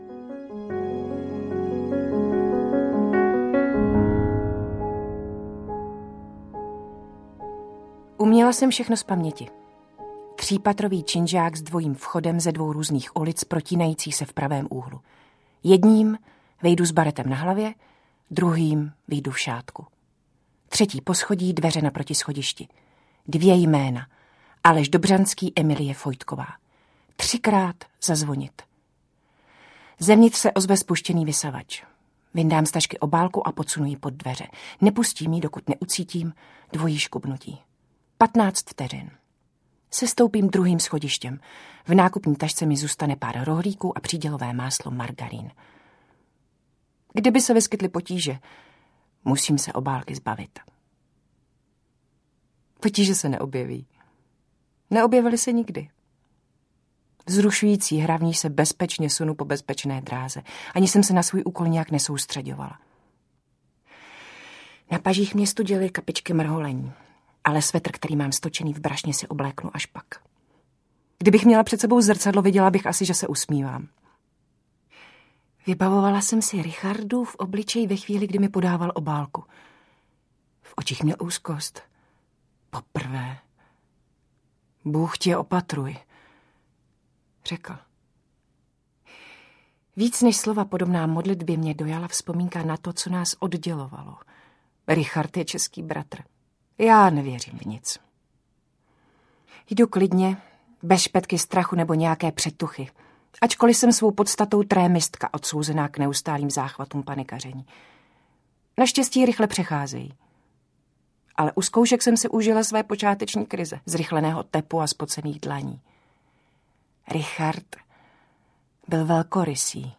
Želary - Jozova Hanule audiokniha
Ukázka z knihy
• InterpretLenka Vlasáková